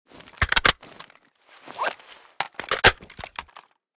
groza_reload.ogg.bak